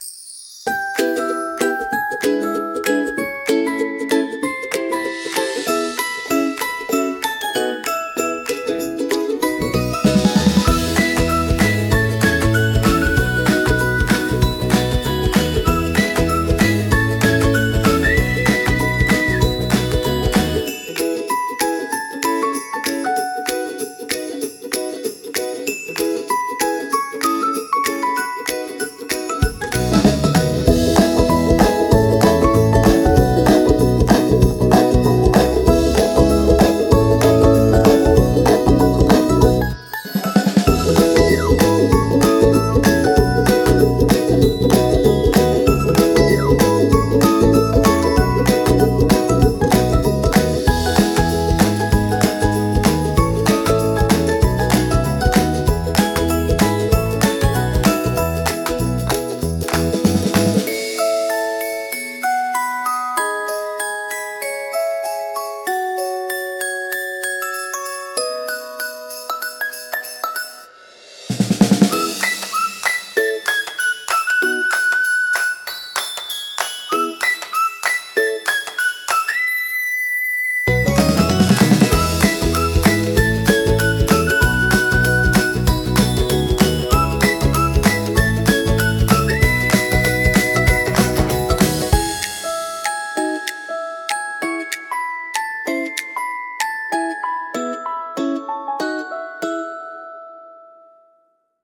Cheerful Kids Tune